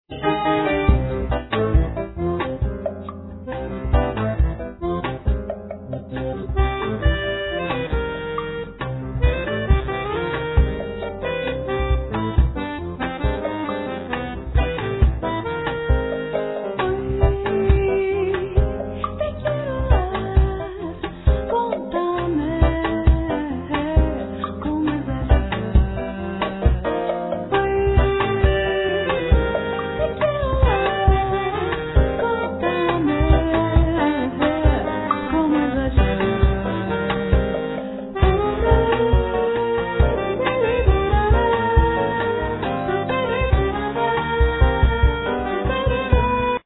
percussion
piano
winds
cello, piano
ethnic winds
composition, piano, voice, acordion, berimbao